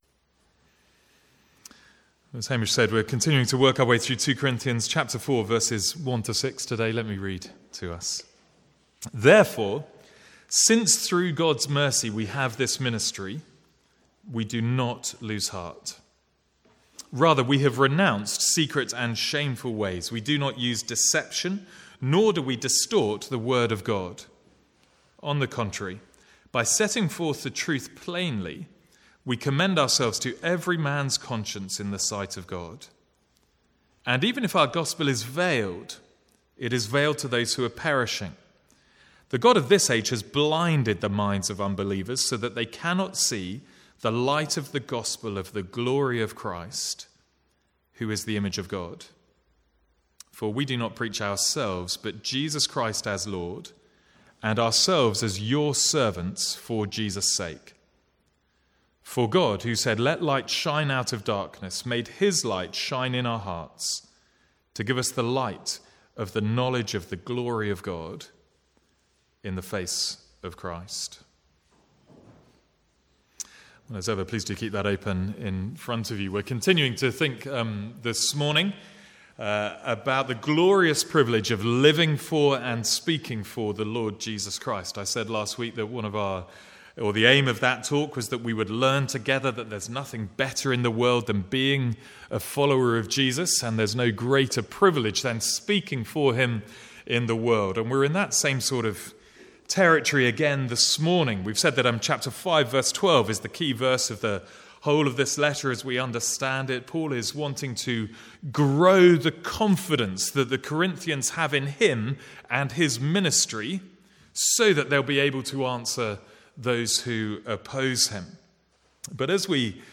Sermons | St Andrews Free Church
From the Sunday morning series in 2 Corinthians.